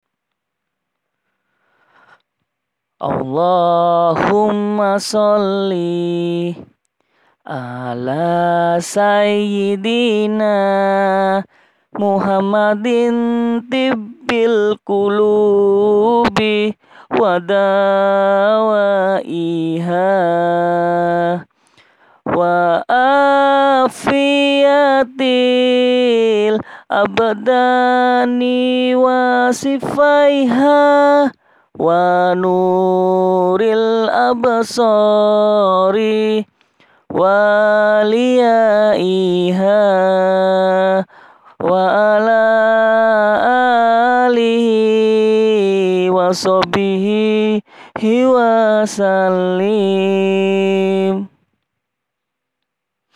Maaf kalau suaranya jelek, ini asli saya rekam sendiri bacaan pujian yang biasanya saya pakai.
Mohon maaf sebelumnya jikalau suara saya jelek :).